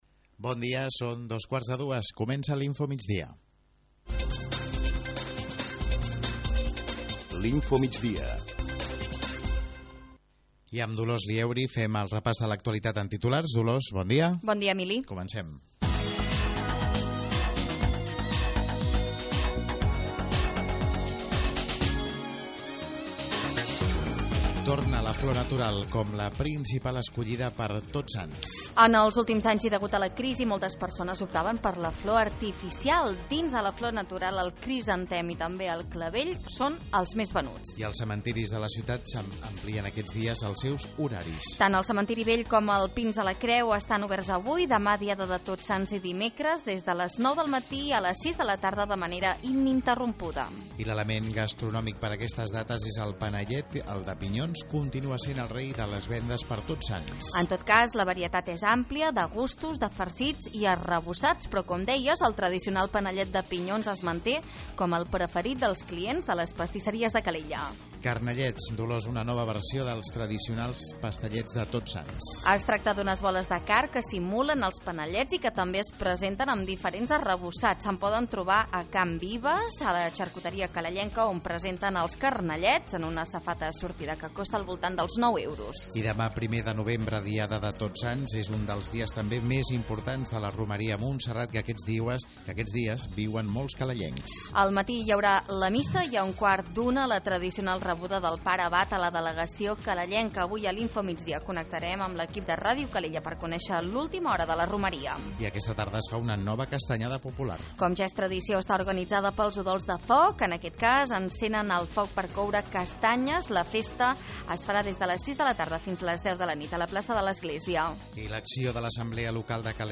Les flors, els panellets i la castanyada popular que se celebra aquesta tarda a la ciutat impulsada pels Udols de Foc han estat els protagonistes de l'informatiu d'avui. També hem connectat amb Montserrat per conèixer l'última hora de la romeria.